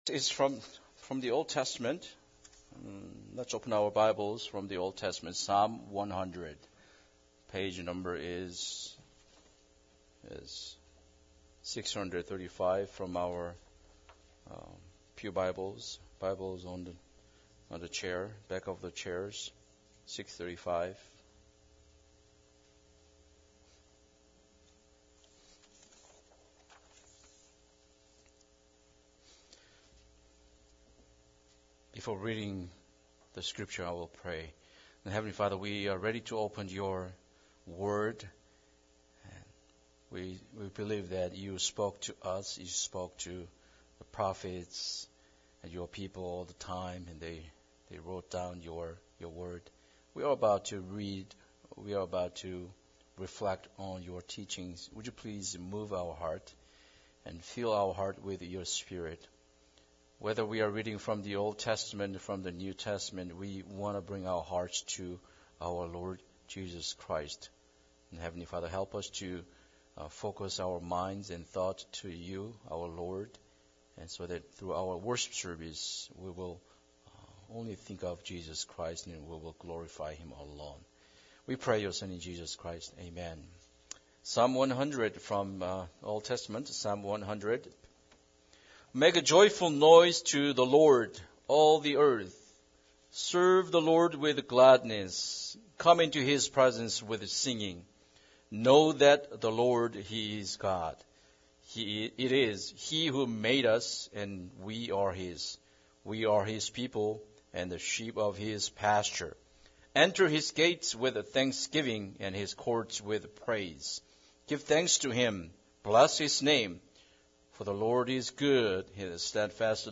Psalm 100 Service Type: Special Service Bible Text